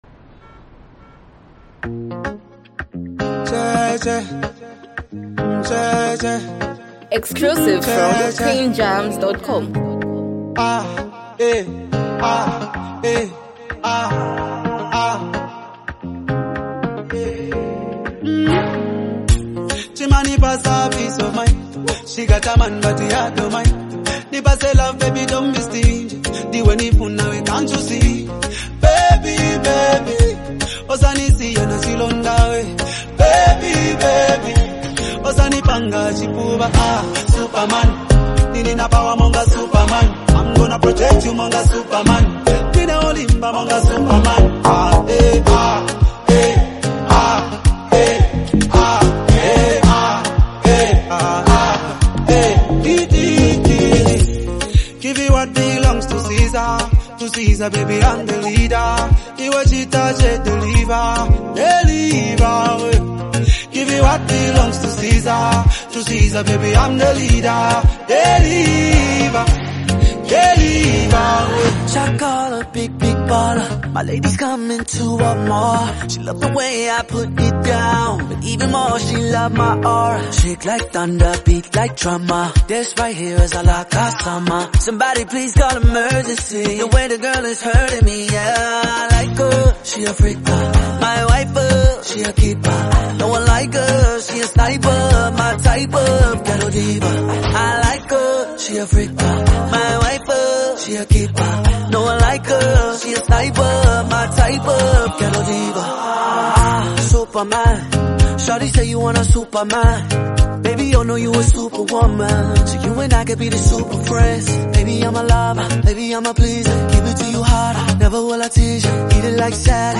bringing in a soulful R&B vibe
heartfelt delivery